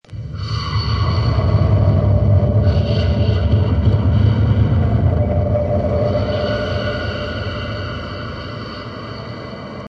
Download Whispering sound effect for free.
Whispering